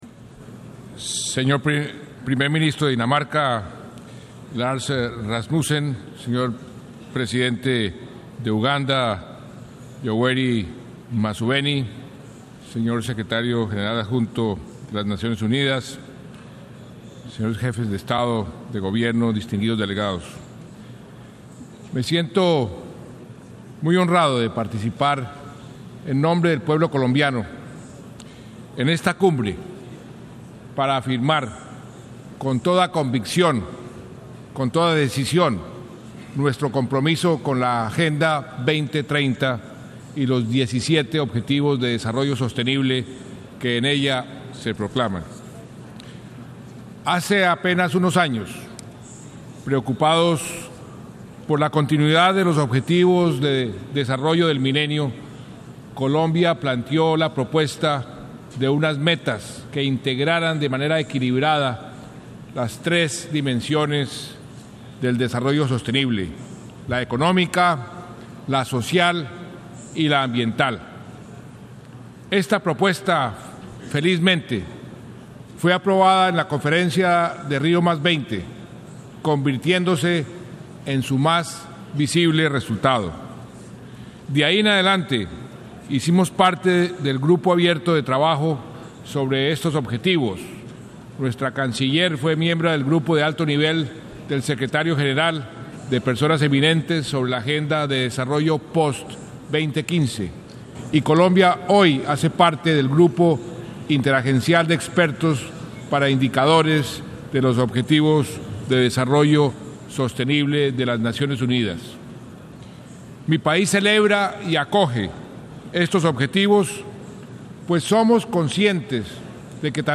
Palabras del Presidente de Colombia Juan Manuel Santos, en la Cumbre de Desarrollo Sostenible de Naciones Unidas | Cubanamera
Nueva York, 25 sep (SIG).